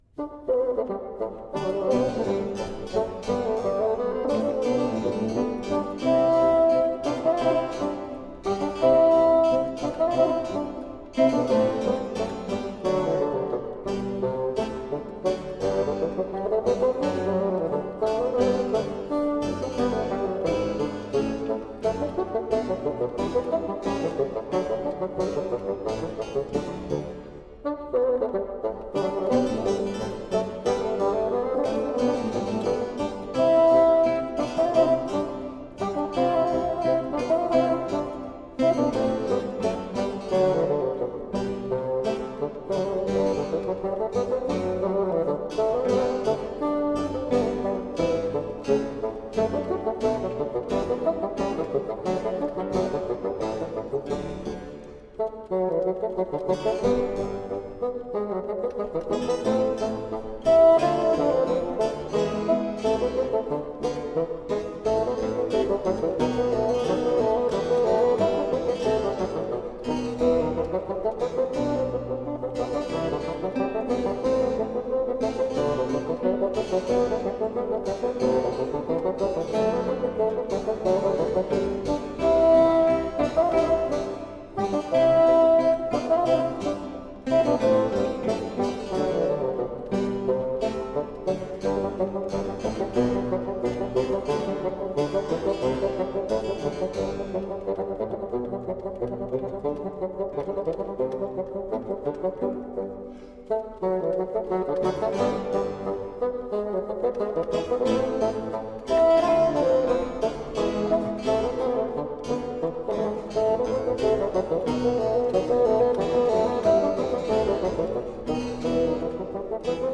Classical, Chamber Music, Baroque, Instrumental, Bassoon
Harpsichord, Organ